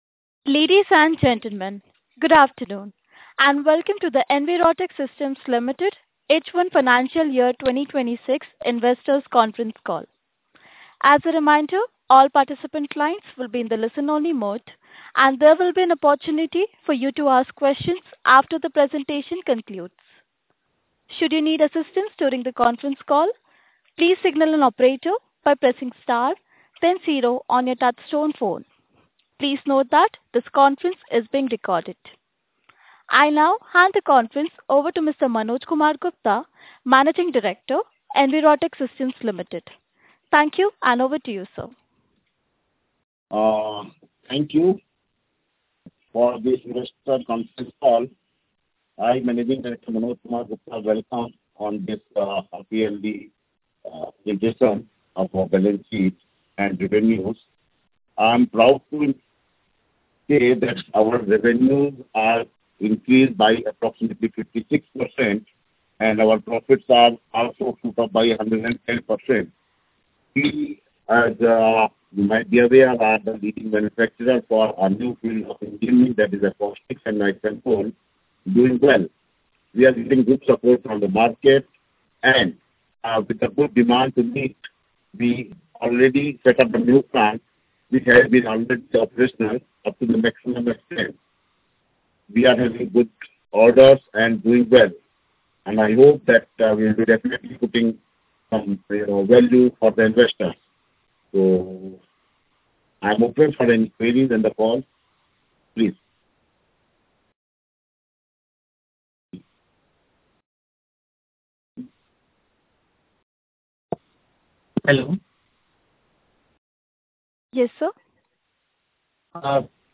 Conference call audio recording